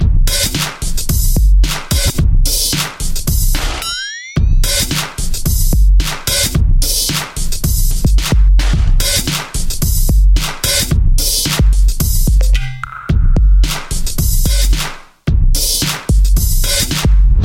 标签： 低音 舞蹈 电子 女声 循环 男声 合成器
声道立体声